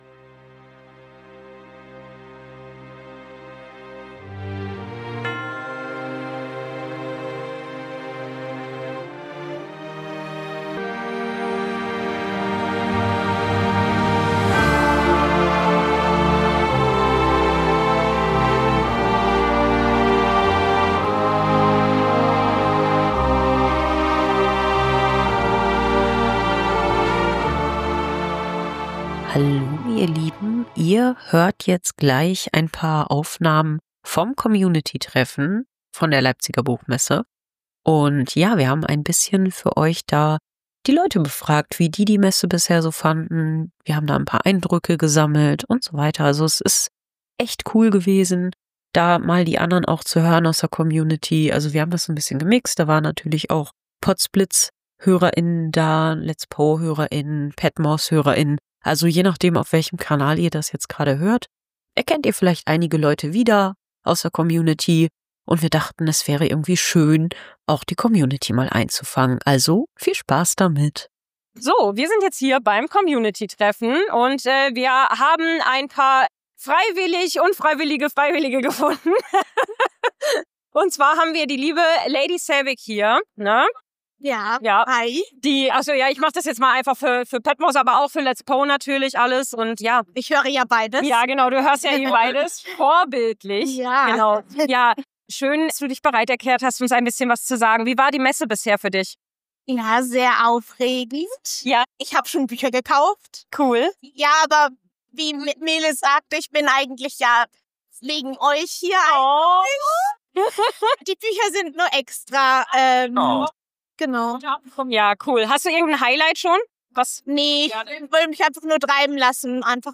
Leipziger Buchmesse Tag 2 - Das große Community Treffen
Intro und Outro Musik wurden mit Udio AI beta erstellt